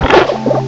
sovereignx/sound/direct_sound_samples/cries/oinkologne_f.aif at master